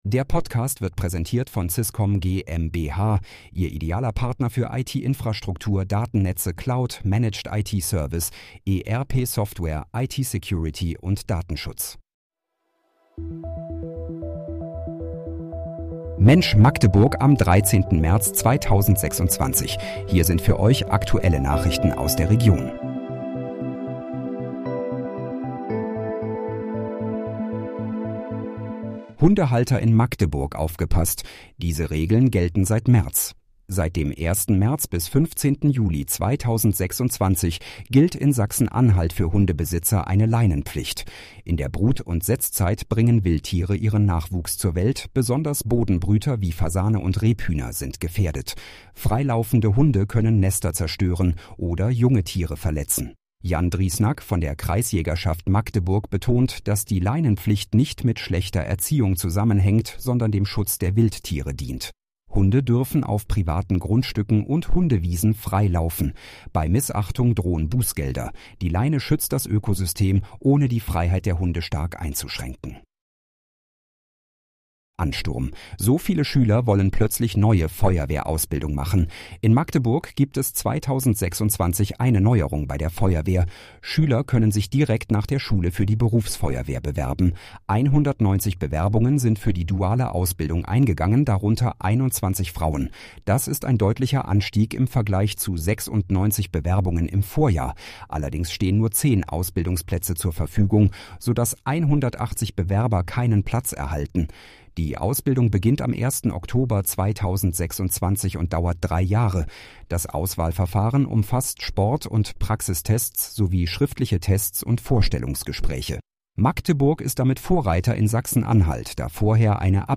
Mensch, Magdeburg: Aktuelle Nachrichten vom 13.03.2026, erstellt mit KI-Unterstützung